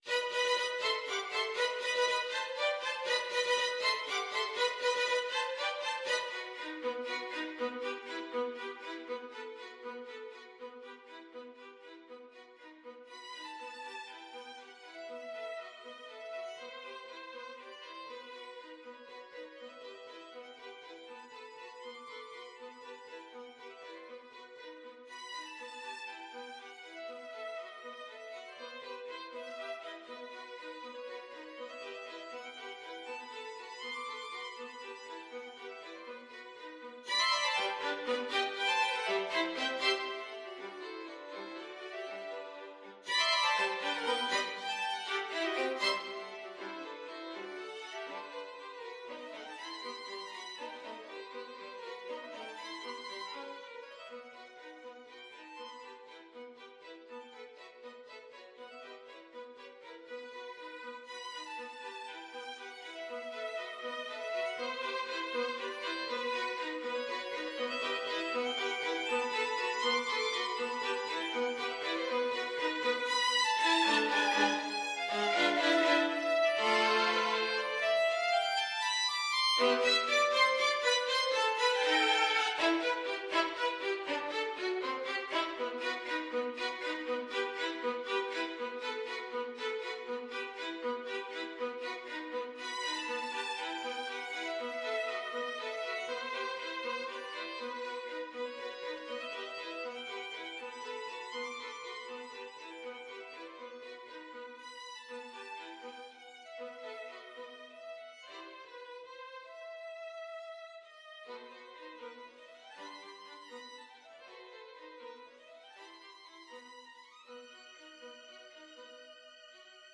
3/8 (View more 3/8 Music)
Allegro vivo (.=80) (View more music marked Allegro)
Violin Trio  (View more Advanced Violin Trio Music)
Classical (View more Classical Violin Trio Music)